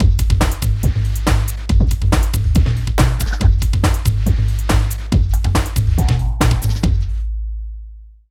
51 LOOP   -L.wav